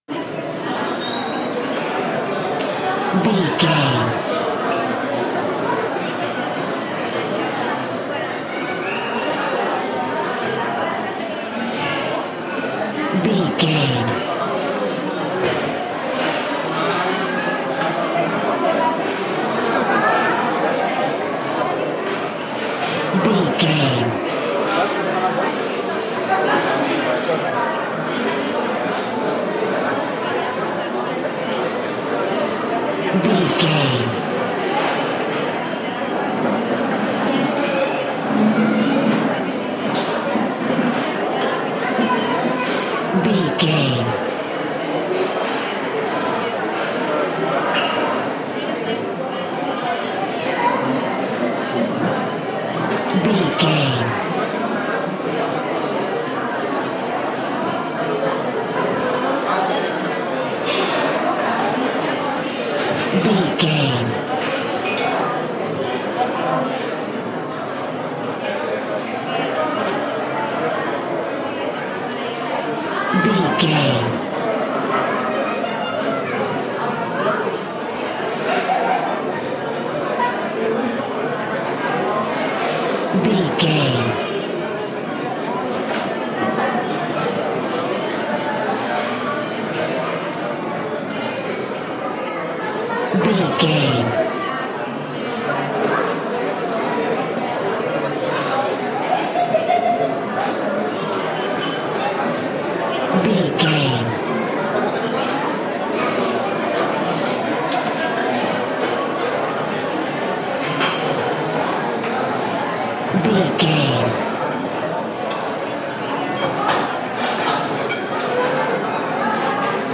Restaurant large crowd
Sound Effects
urban
chaotic
ambience